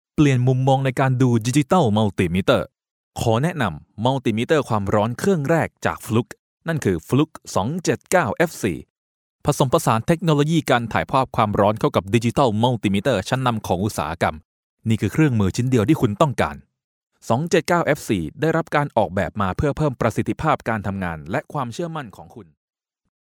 Professionelle Sprecher und Sprecherinnen
Männlich